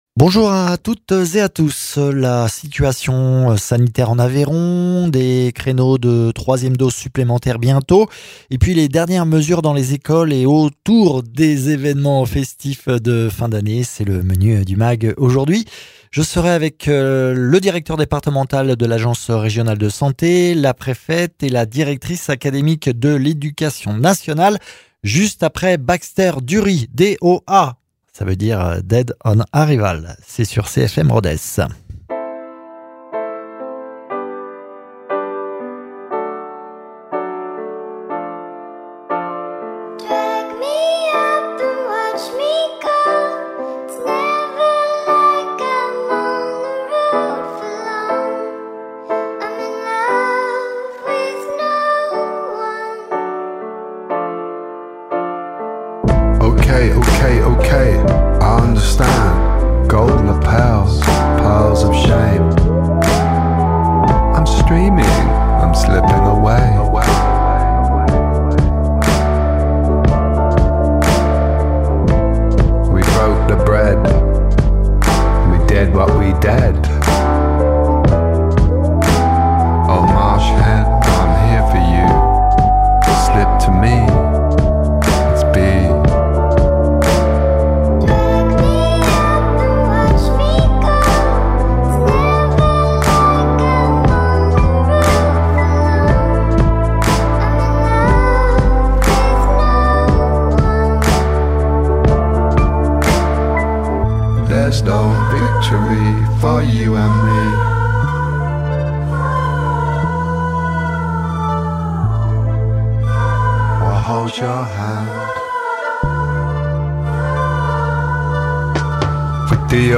Invité(s) : Benjamin Arnal, Directeur départemental de l’ARS, Valérie Michel-Moreaux, Préfète de l’Aveyron ; Claudine Lajus, Directrice départementale de l’éducation nationale